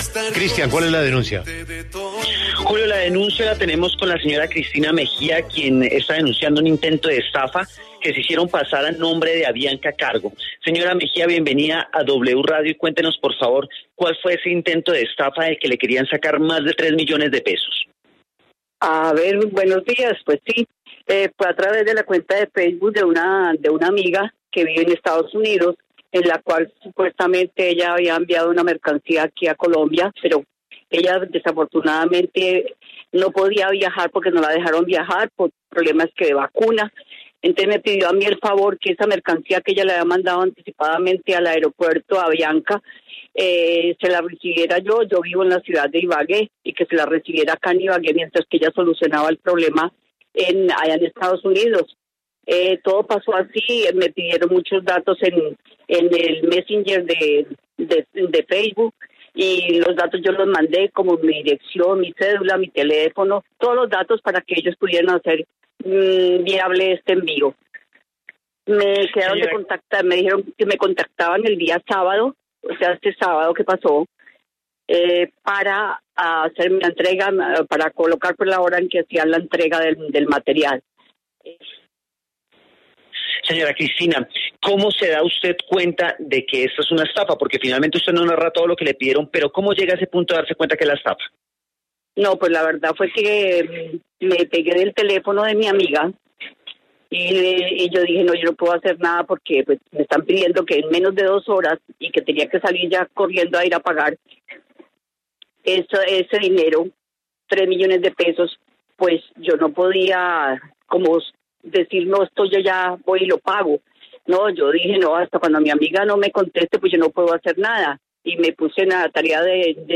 Desde W Radio se procedió a llamar al número de donde contactaron a la ciudadana, allí como respuesta encontró una persona que aseguraba trabajar para Avianca Cargo y generar procedimientos de entrega de mercancía.